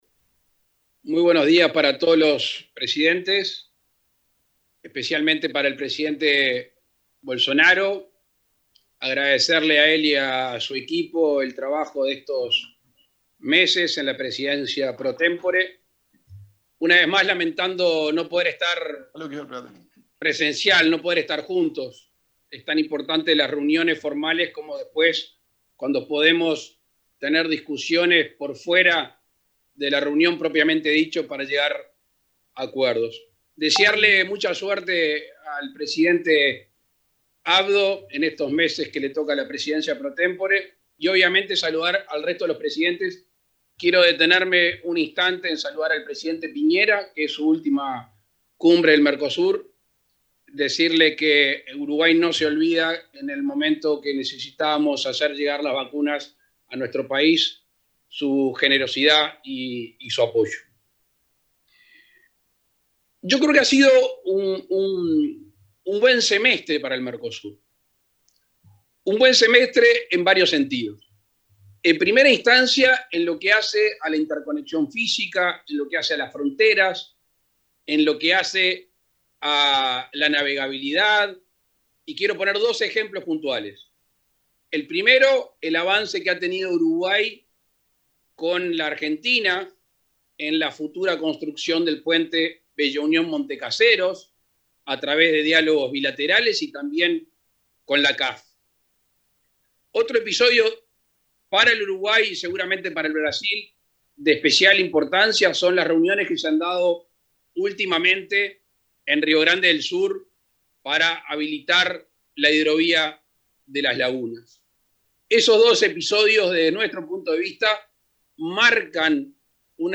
Palabras del presidente Luis Lacalle Pou
El presidente Luis Lacalle Pou participó este viernes 17, por videoconferencia, en la Cumbre del Mercosur en la que el mandatario paraguayo, Mario